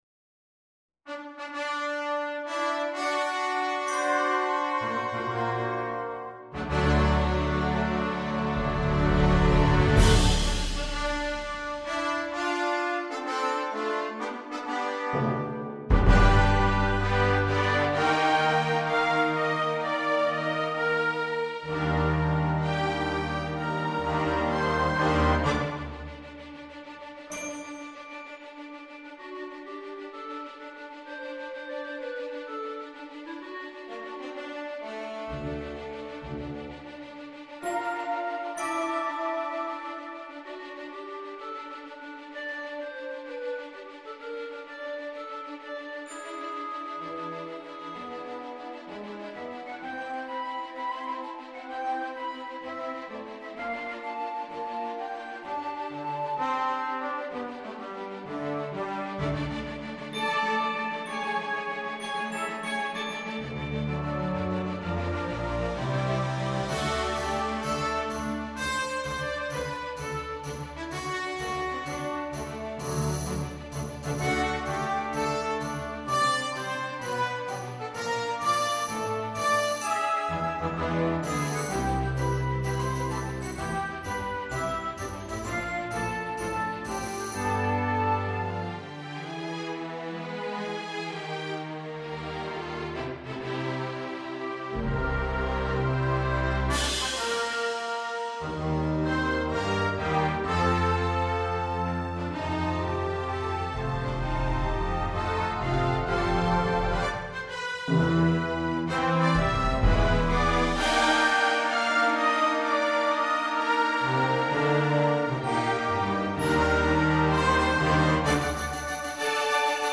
for orchestra
Live Premiere Recording
NotePerformer MIDI Demo